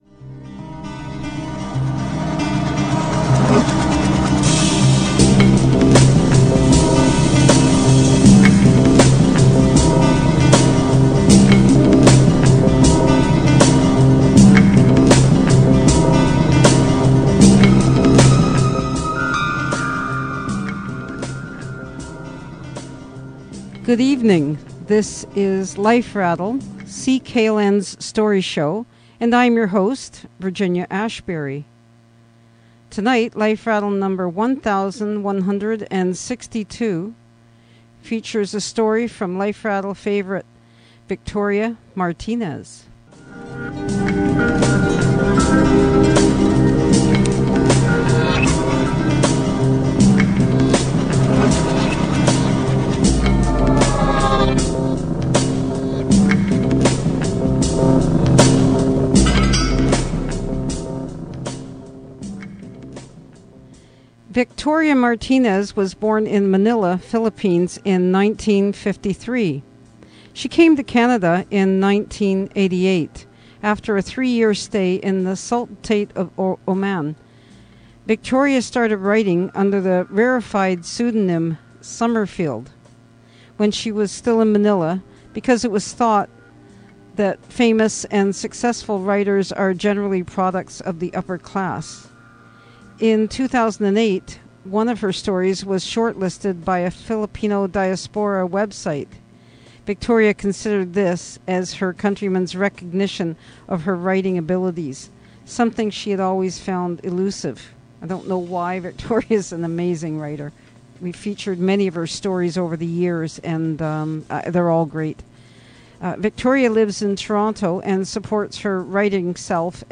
aired on CKLN Sunday, July 24, 2011